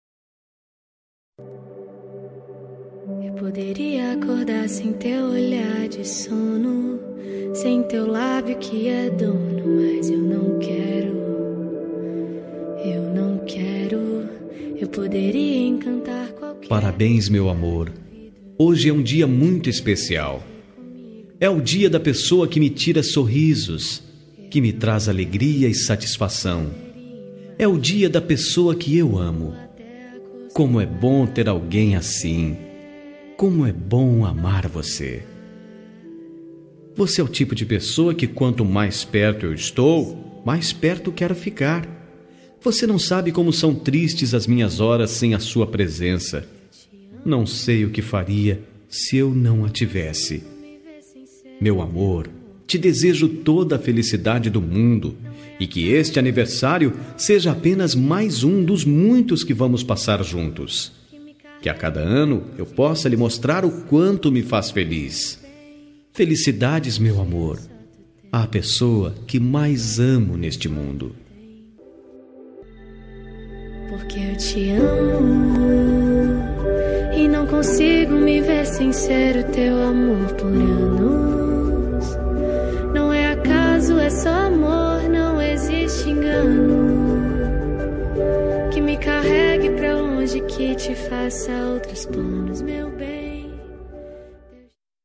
Telemensagem de Aniversário Romântico – Voz Masculina – Cód: 202129 – Linda